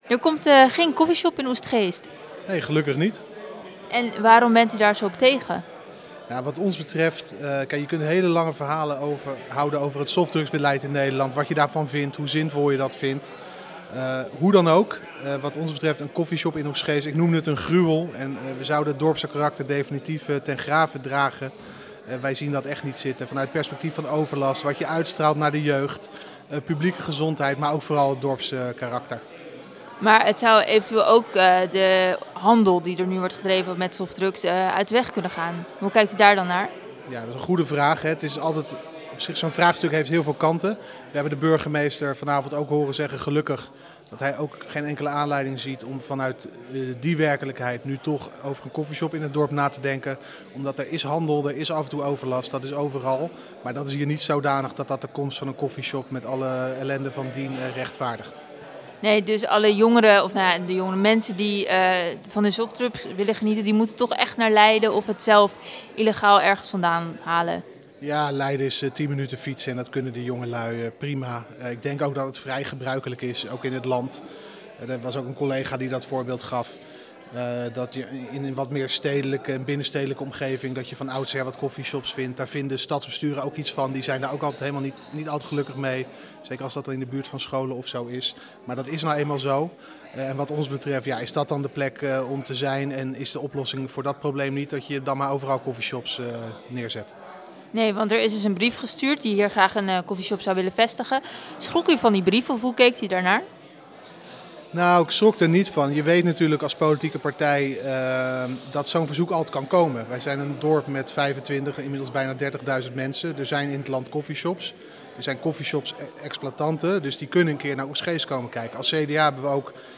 Cda-raadslid Tobias van der Hoeven over coffeeshop in Oegstgeest.